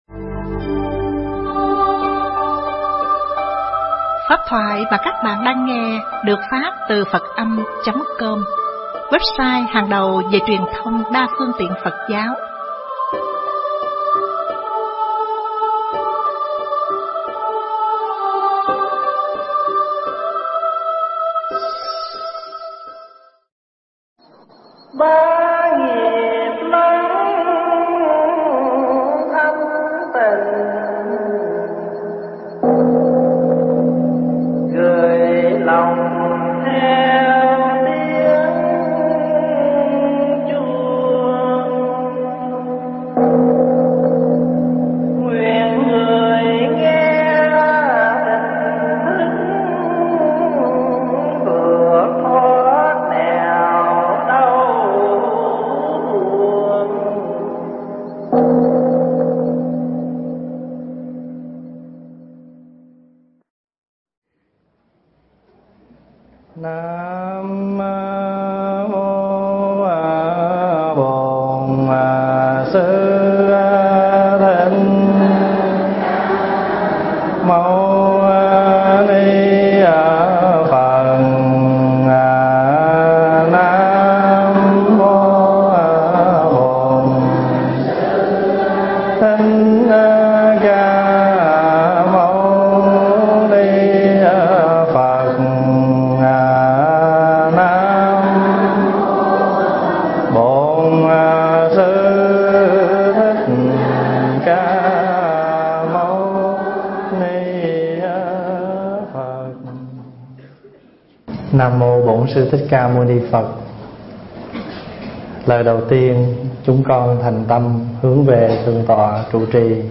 Người Nghịch Ta Chẳng Nghịch (Vấn Đáp)
thuyết giảng tại Melbourne